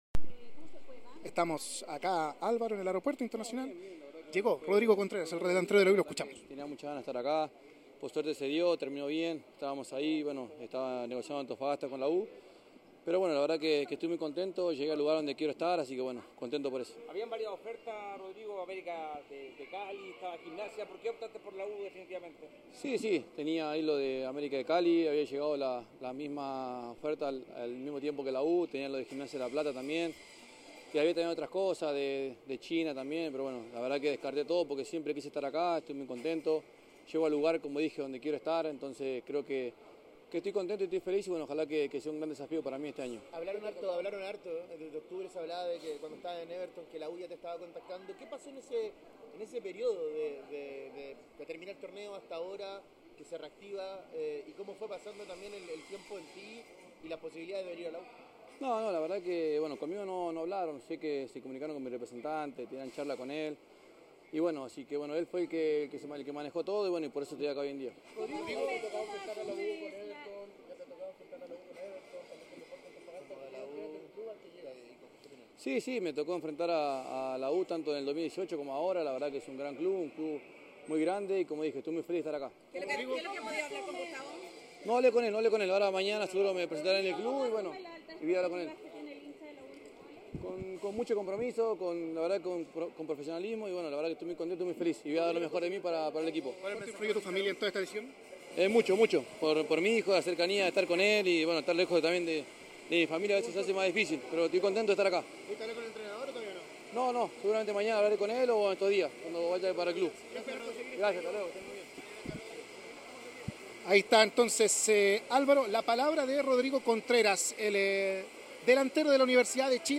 Tenía muchas ganas de estar acá, por suerte se dio, terminó bien. Llegué al lugar donde quiero estar, estoy muy contento por eso”, aseguró el delantero en el Aeropuerto Internacional de Santiago.